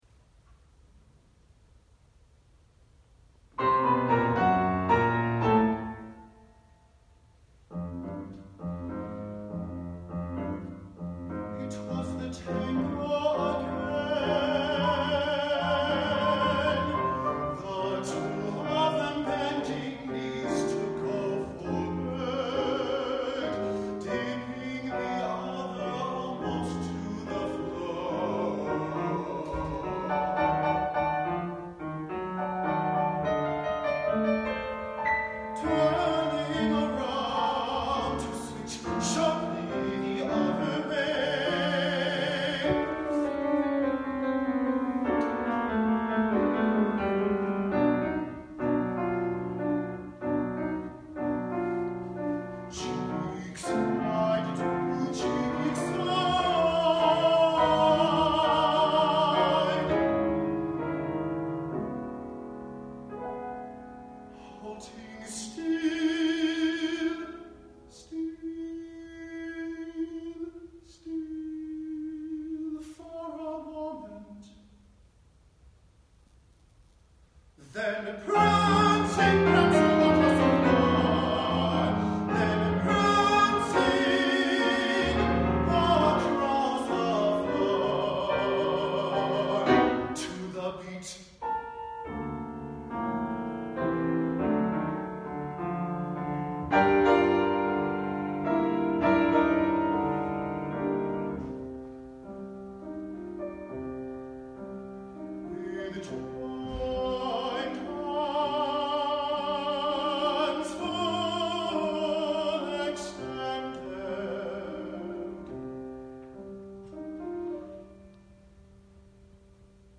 Tenor (or Soprano) & Piano